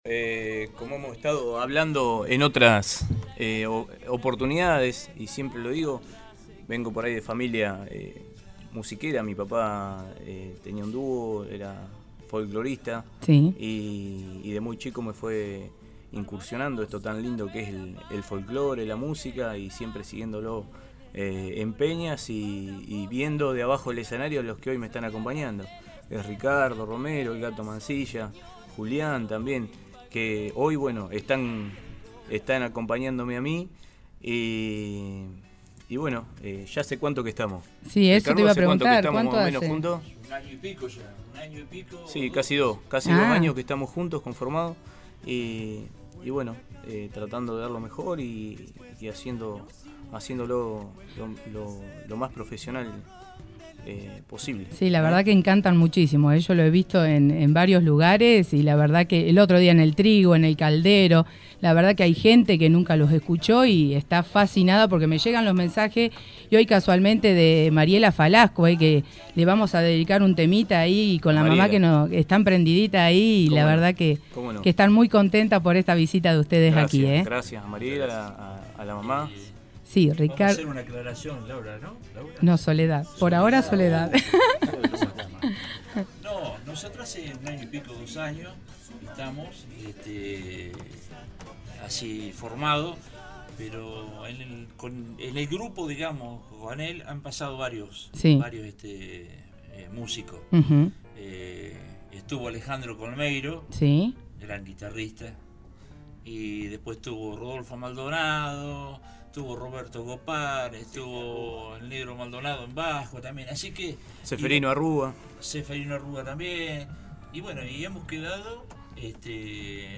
grupo folklórico
Hubo zambas, chacareras y hasta alguna cumbia.